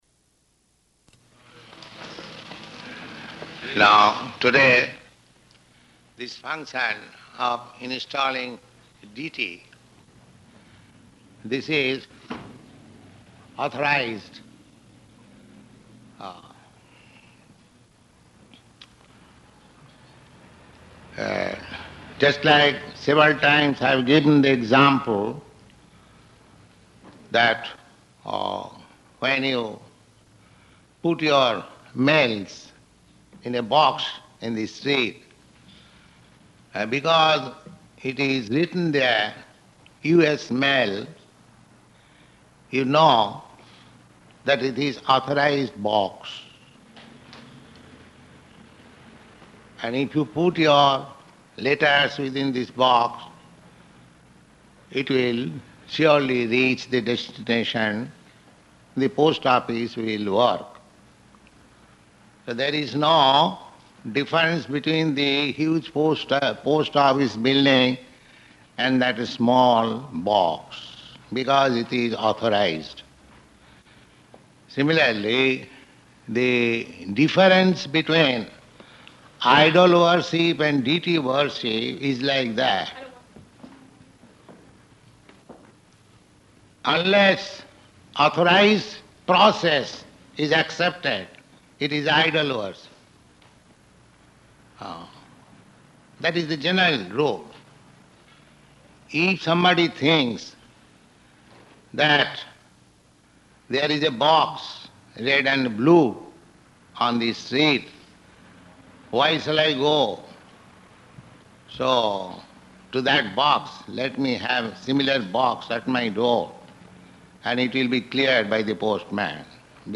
Type: Lectures and Addresses
Location: Los Angeles